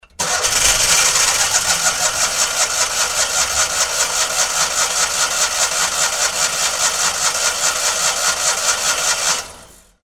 odpalany_maluch.mp3